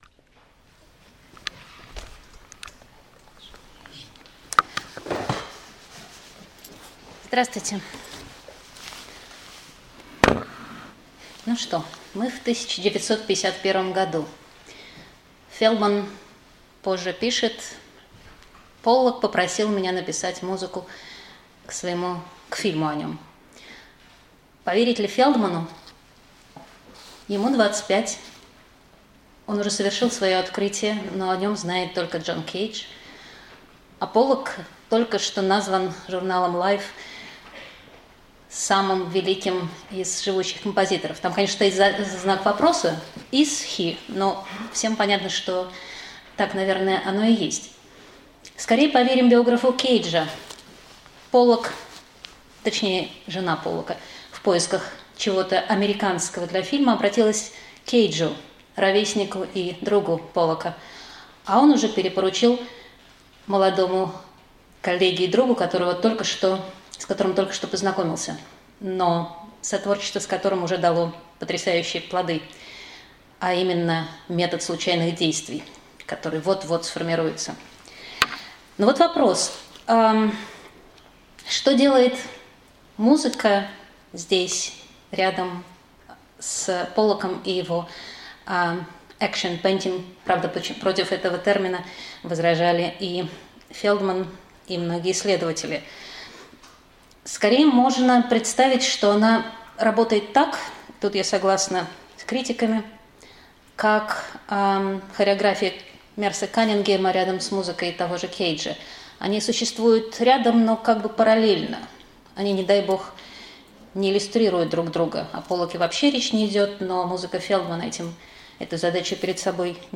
Аудиокнига Мортон Фелдман и абстрактный экспрессионизм | Библиотека аудиокниг